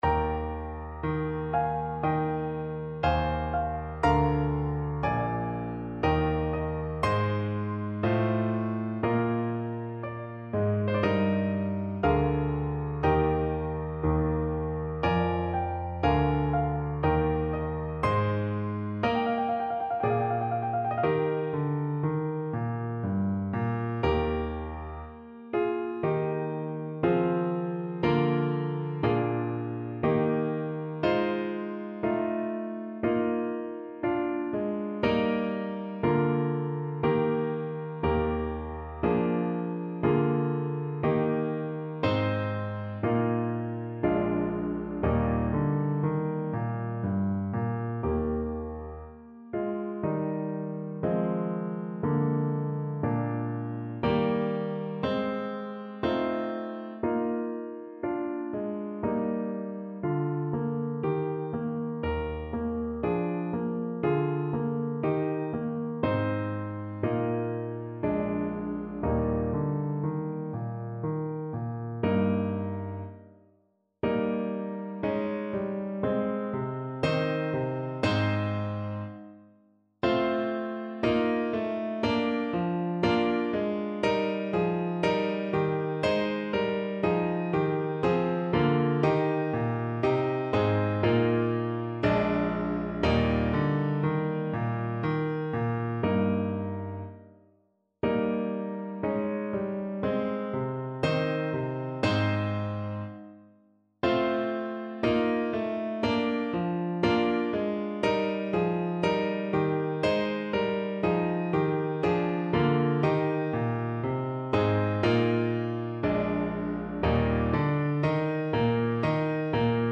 Alto Saxophone
Andante =80
3/4 (View more 3/4 Music)
C5-Eb6
Classical (View more Classical Saxophone Music)